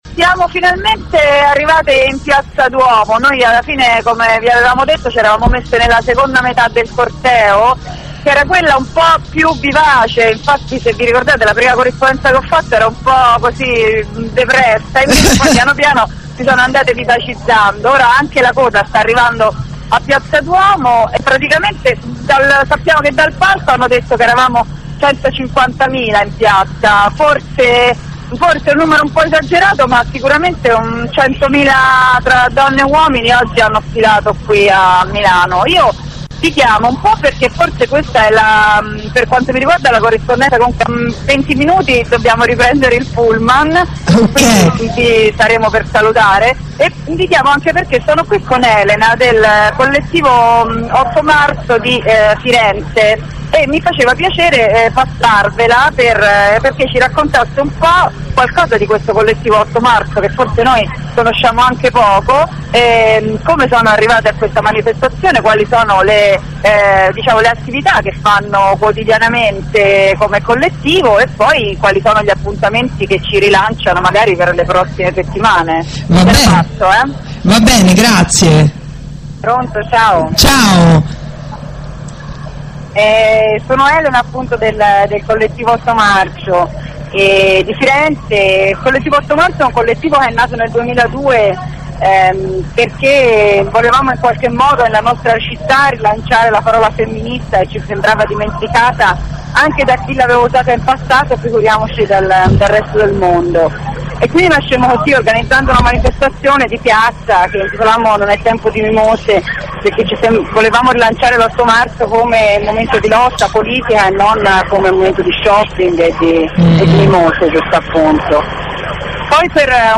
prima corrispondenza dal corteo di milano, elenco striscioni e partecipazione.